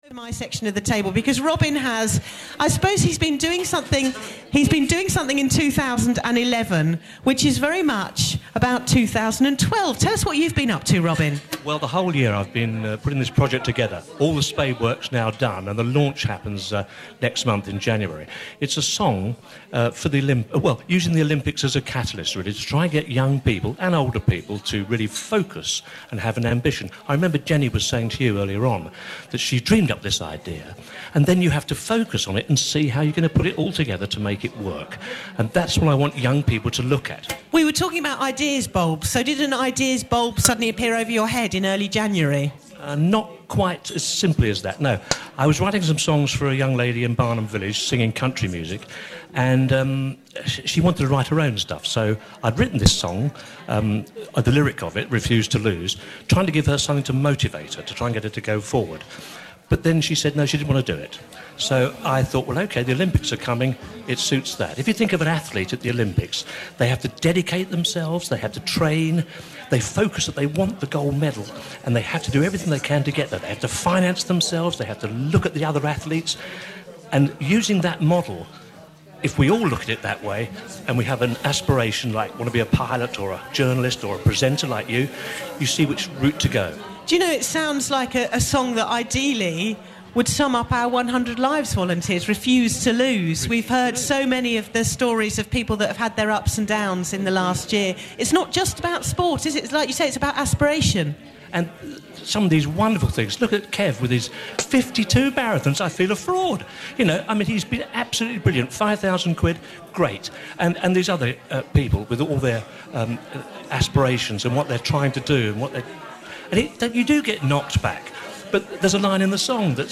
The following MP3 clips are taken from the BBC Radio Surrey and Sussex '100 Lives' feature where you can follow the Refuse to Lose story as it unfolds.
BBC Radio Surrey and Sussex interview 6 - 19th December 2011